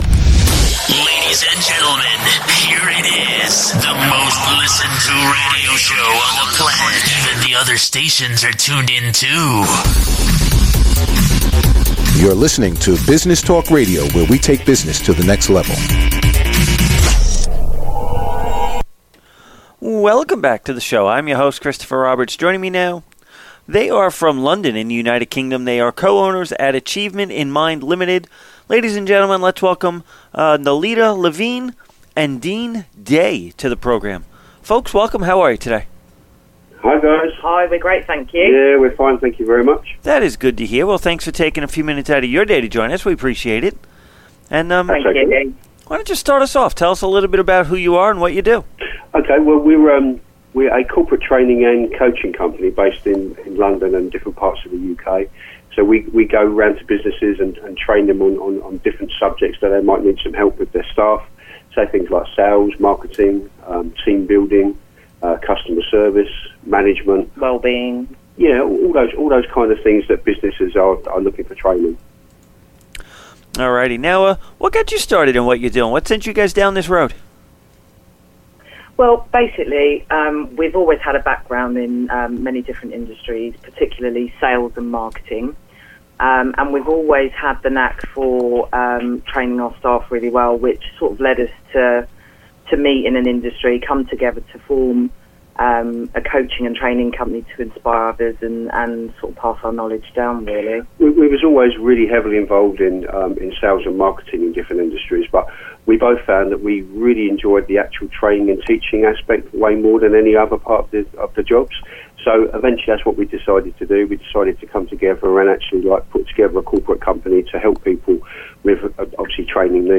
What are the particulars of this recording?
New York Radio Interview The AIM Experience